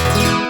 Opus versions of the sound pack.
SpeechOn.opus